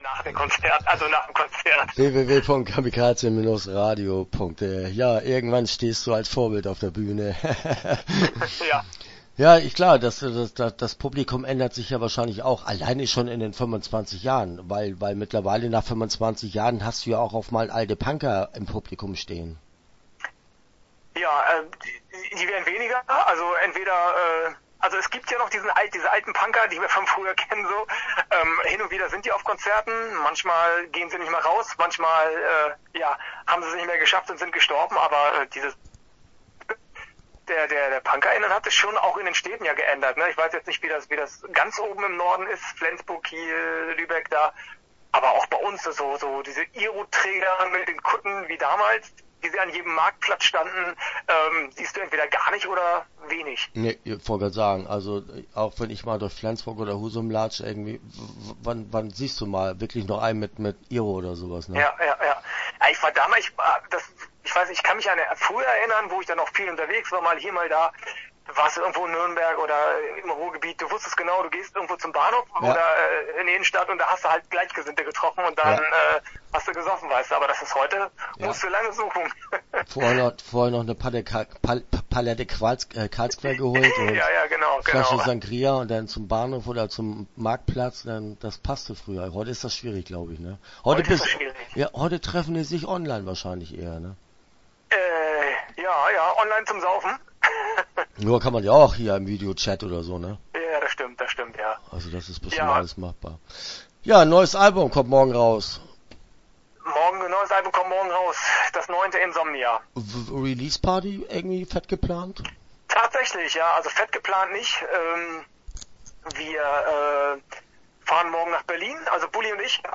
Start » Interviews » Alarmsignal